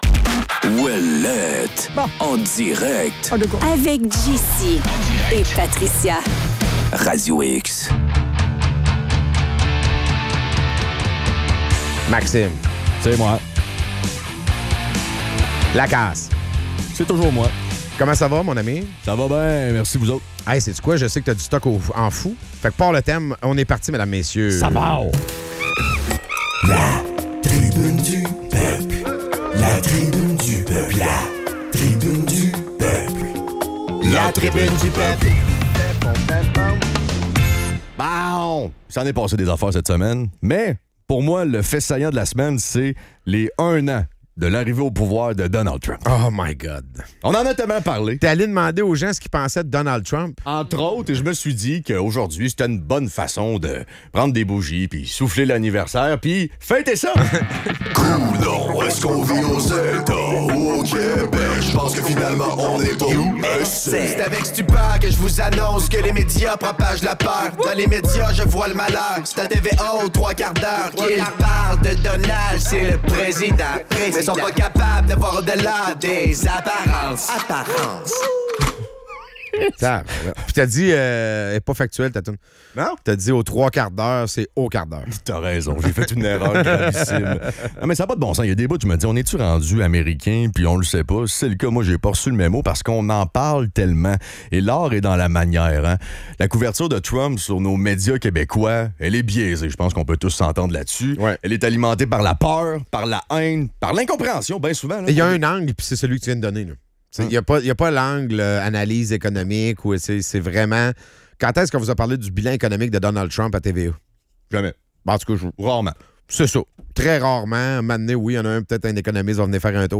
Les animateurs discutent des perceptions des Québécois face à Donald Trump, notamment l'impact des médias sur la peur et l'émotion entourant sa présidence. Ils soulignent une couverture biaisée qui manque d'analyses économiques et qui alimente des stéréotypes négatifs. À travers des témoignages de citoyens, ils explorent comment l'insécurité et l'imprévisibilité de Trump contribuent à un climat de peur au Québec.